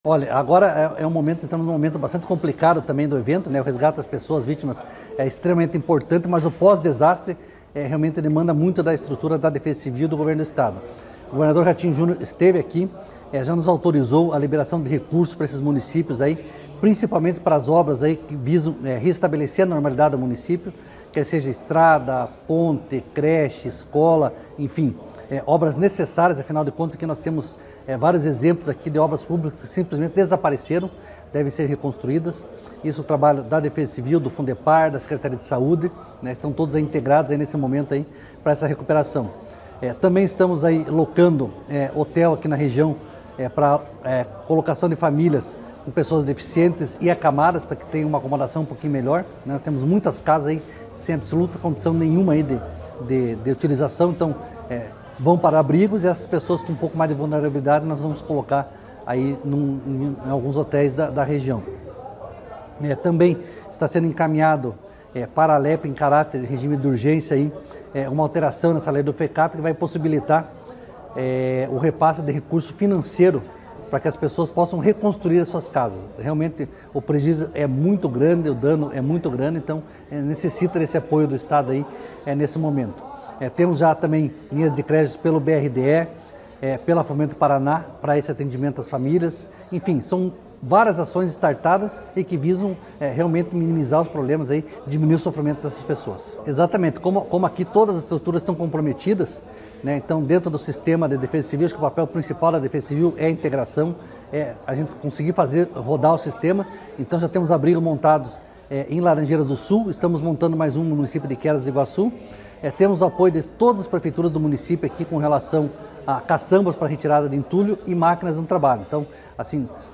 Sonora do chefe da Defesa Civil Estadual, coronel Fernando Schunig, sobre as medidas adotadas pelo Estado no atendimento às vítimas do Tornado